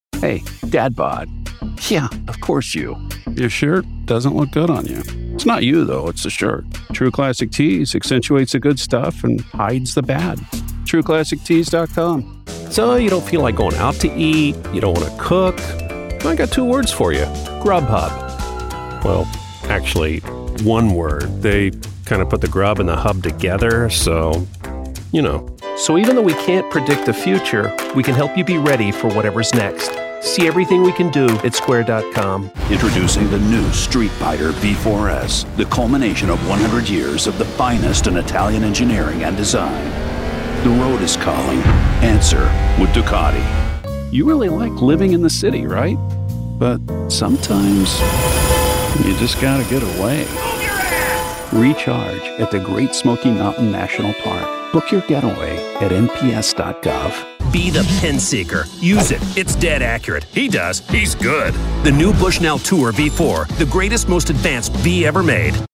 Englisch (Amerikanisch)
Natürlich, Unverwechselbar, Vielseitig, Freundlich, Warm
Kommerziell